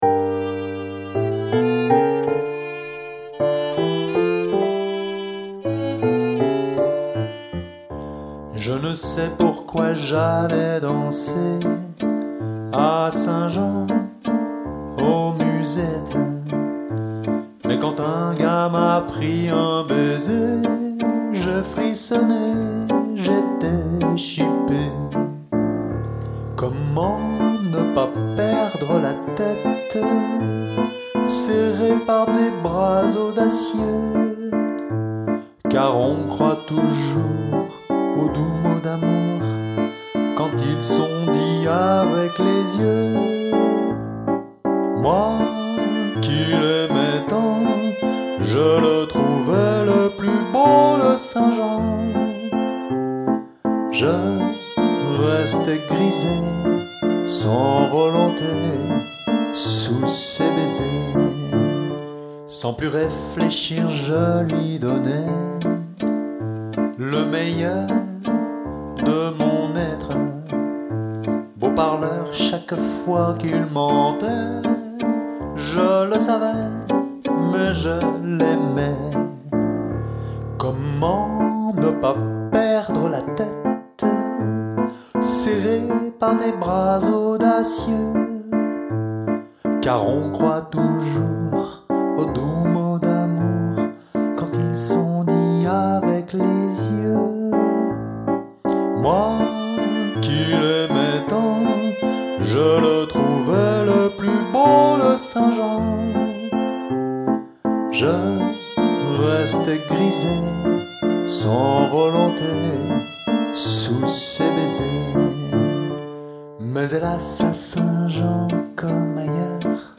le Duo Orphée en concert à la Maison de Retraite de La Pastorale,
Une après-midi de fête, à l’approche de Noël, à La Motte-Servolex, le 19 décembre 2013 à 15h.
Violon
Piano et Chant